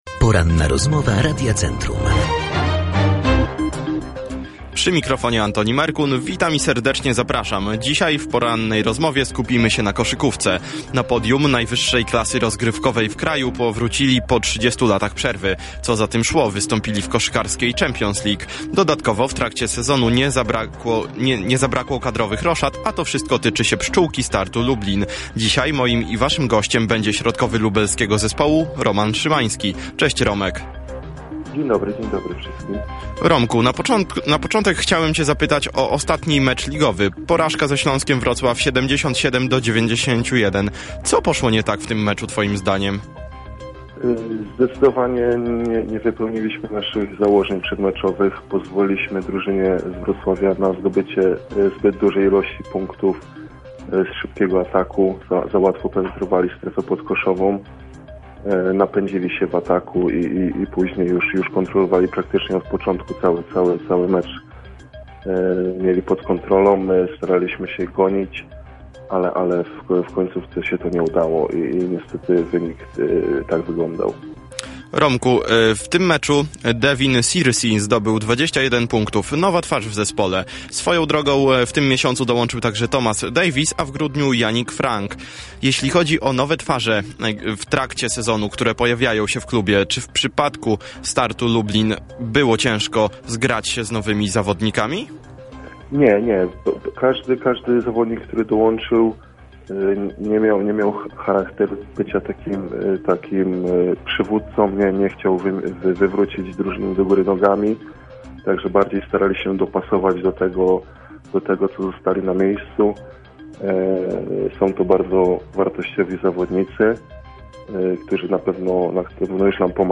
Cała Poranna Rozmowa Radia Centrum dostępna jest poniżej.